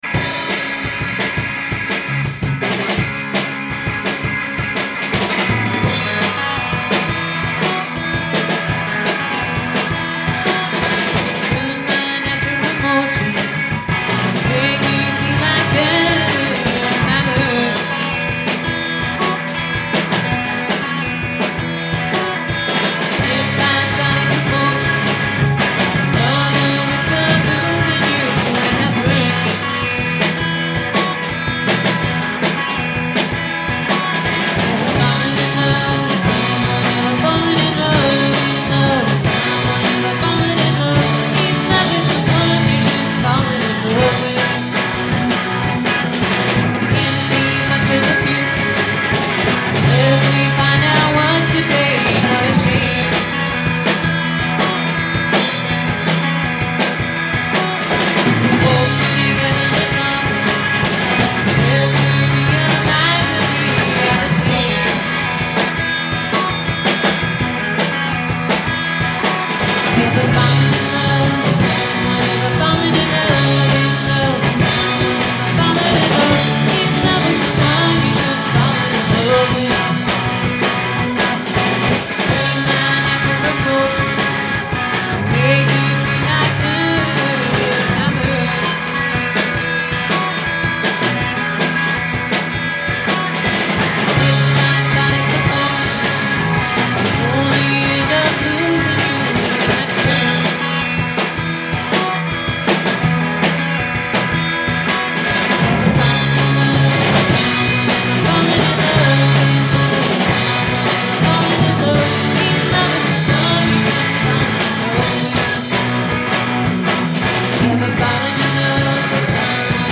Just Some Demos We Recorded in Our Basement
drums, percussion
keyboards, saxophone, vocals